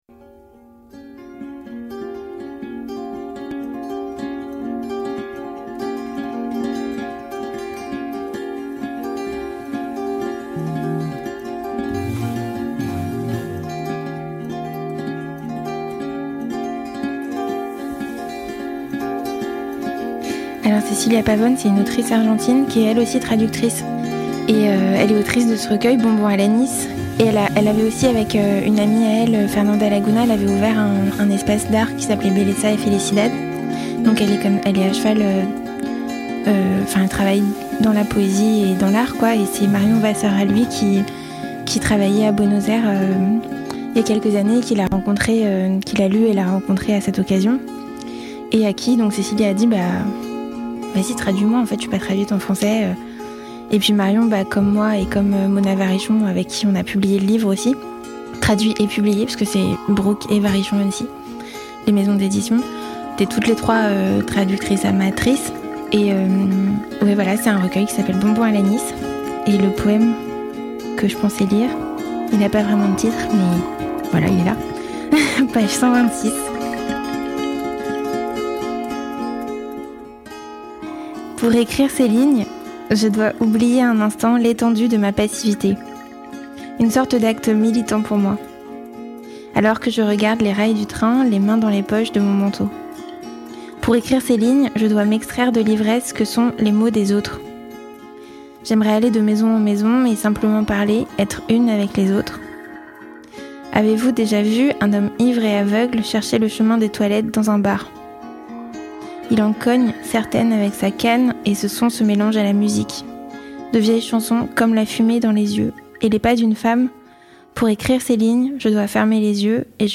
Lecture de Non-noyées d'Alexis Pauline Gumbs, publié en français par les édition Burn-Août et les Liens qui libèrent dans une très belle traduction.
Type Entretien